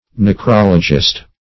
\Ne*crol"o*gist\